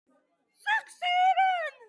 4h kaka 3 Meme Sound Effect